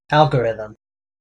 En-us-algorithm.ogg.mp3